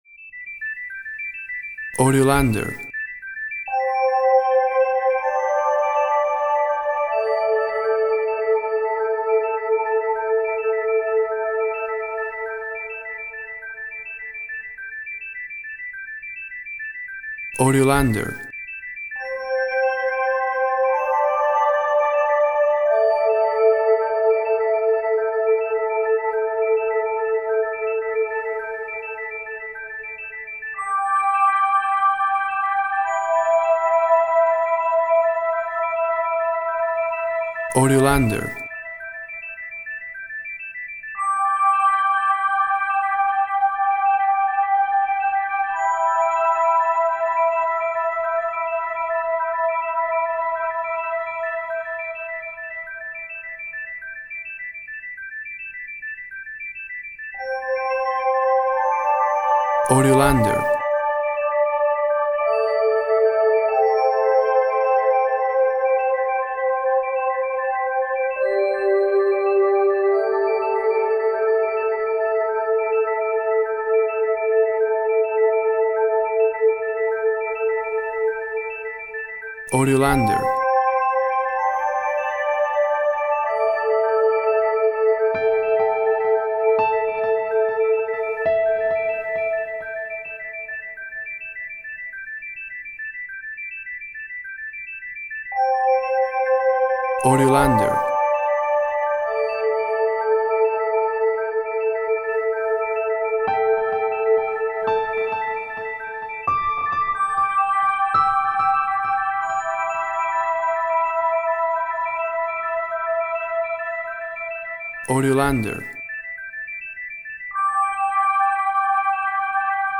Relaxing sounds in the vastness.
Tempo (BPM) 48